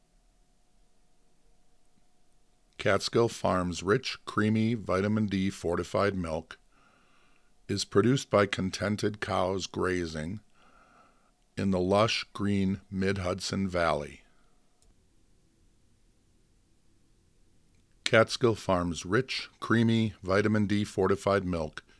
I ran into something similar with my experiments recording in my garage. That worked remarkably well—cardboard boxes make terrific soundproofing—except for the Metrobus and occasional noisy car.